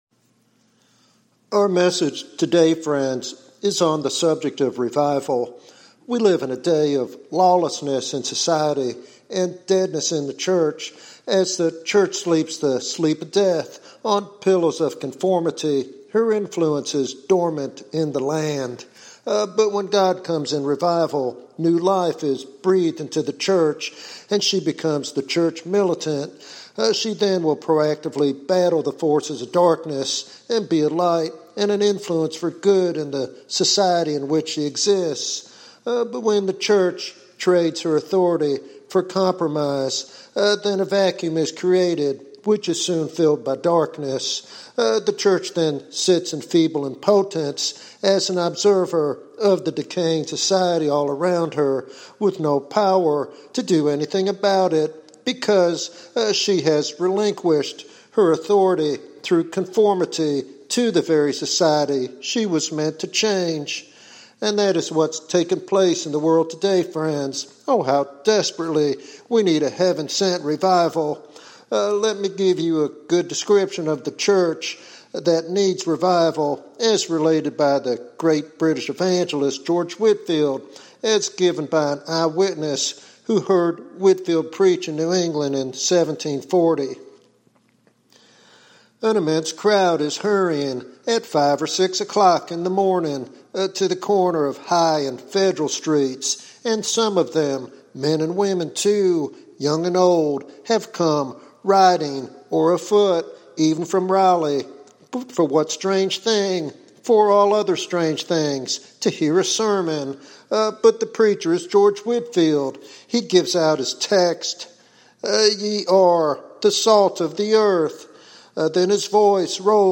In this powerful sermon